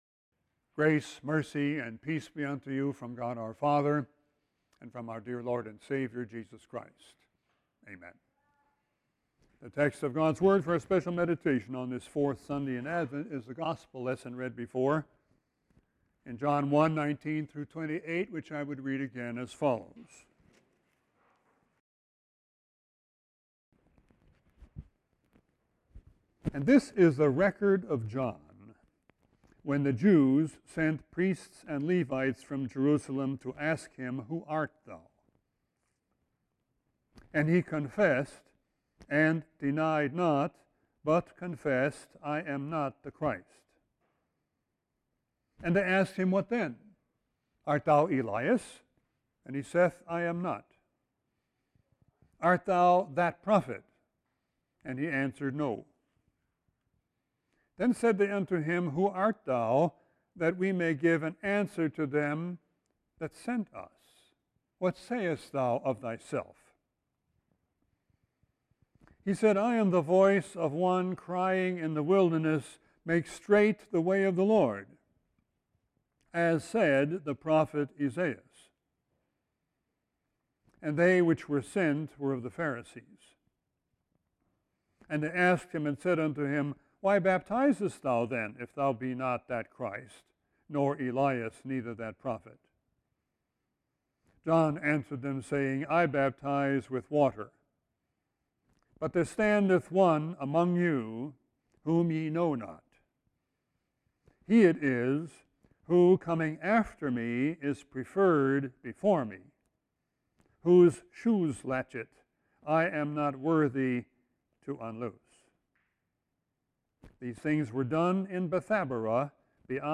Text: John 1:19-28 This text will be replaced by the JW Player Right click & select 'Save link as...' to download entire Sermon video Right click & select 'Save link as...' to download entire Sermon audio